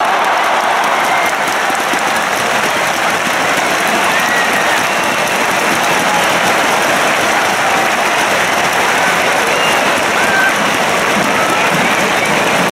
毎年8月に開催している高校野球の大歓声。鼓膜が破れるのではと感じるほどの凄い大歓声。